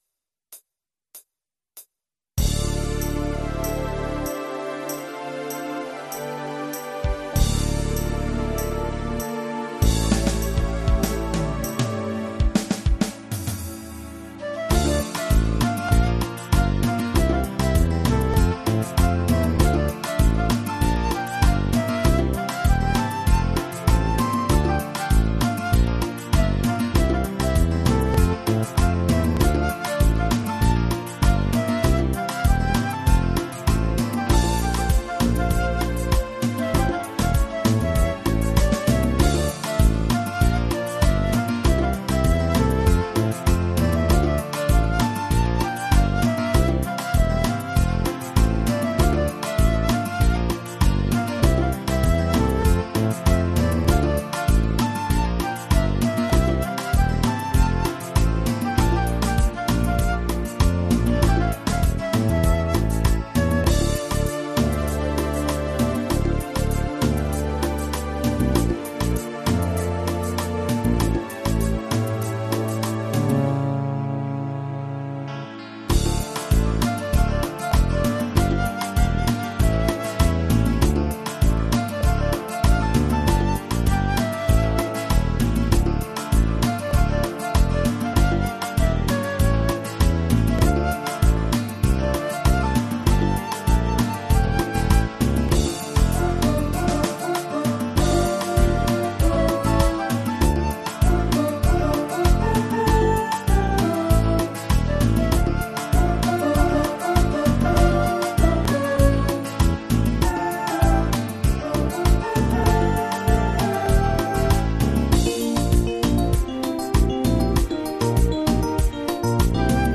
version instrumentale multipistes
au format MIDI pro.